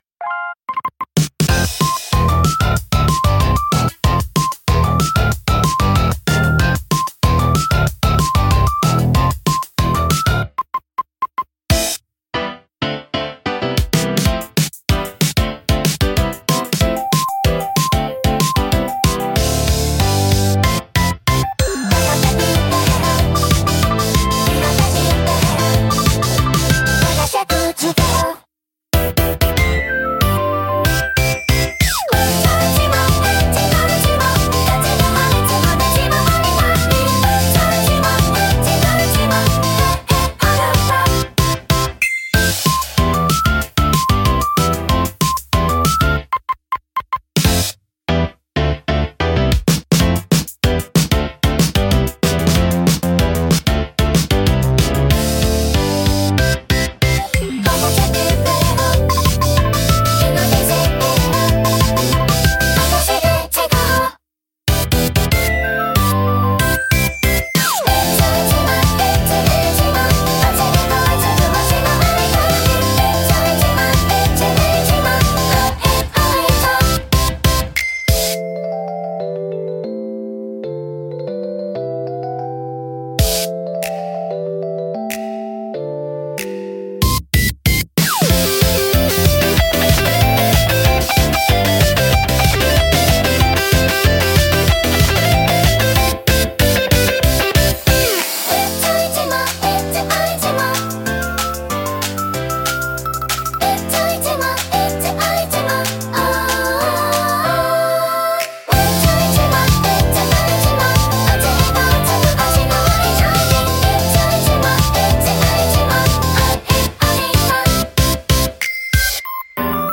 元気で前向きな印象を与え、若年層やファミリー向けコンテンツに特によく合います。